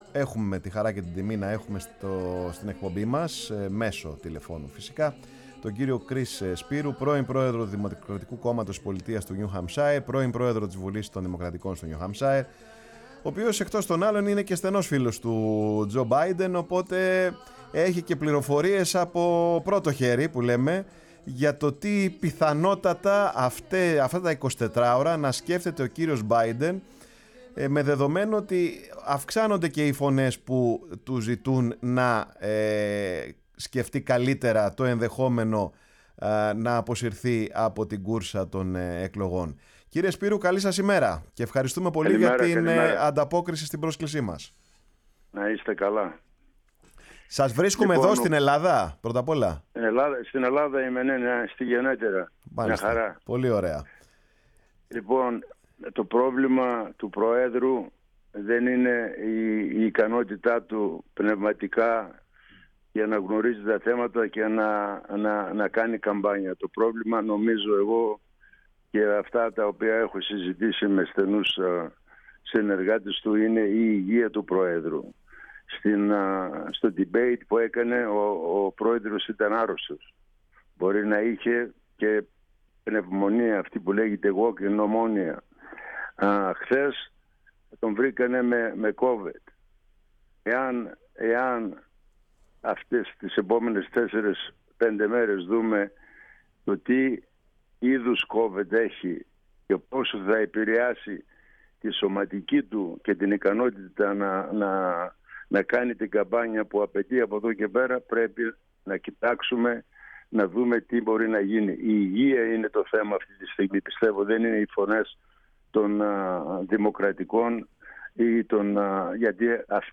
Ο Κρις Σπύρου στην εκπομπή “Πάρε τον Χρόνο σου”| 18.07.2024